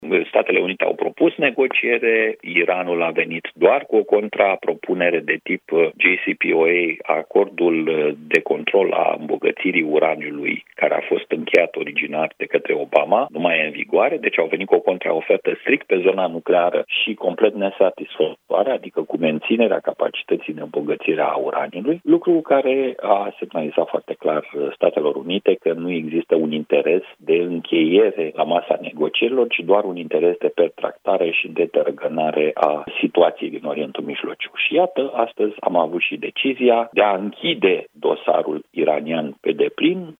a declarat analistul de politică externă